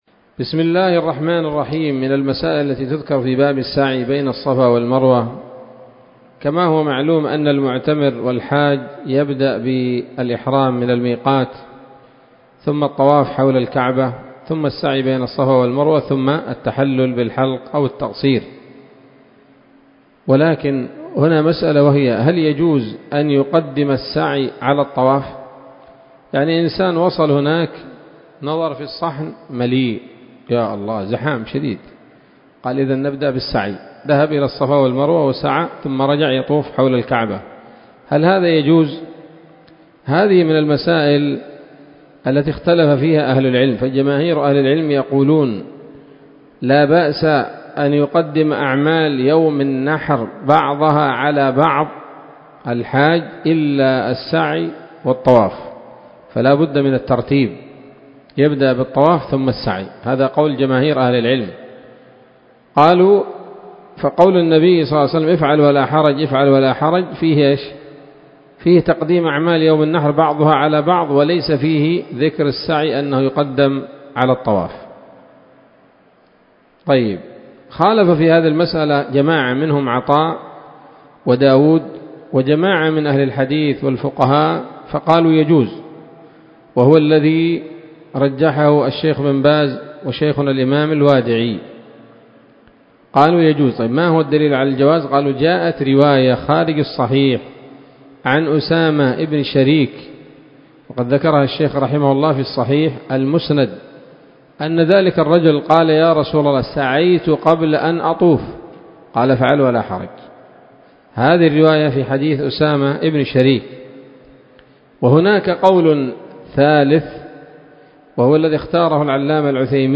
الدرس السابع عشر من كتاب الحج من السموط الذهبية الحاوية للدرر البهية